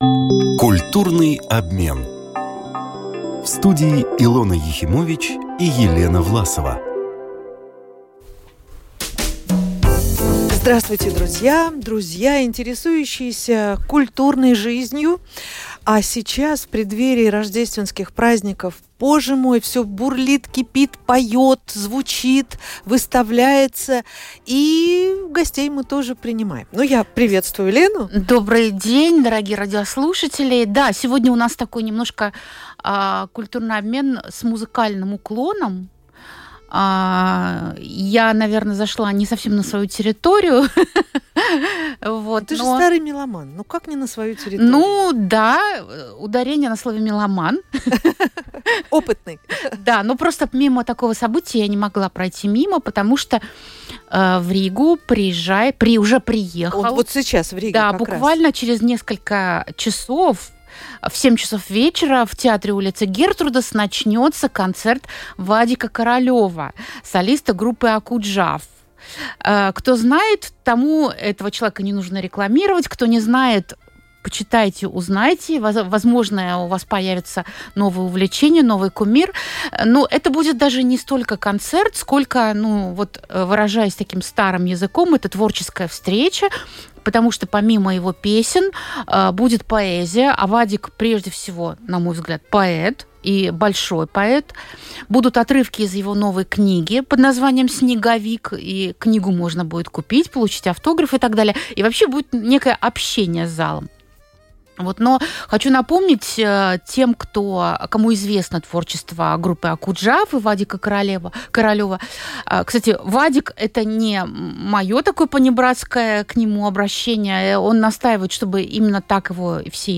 В интервью рубрике “Культурный обмен” Вадик рассказал о том, как ему пришлось начать сольную карьеру, полюбить гитару и перейти в формат “квартирников”.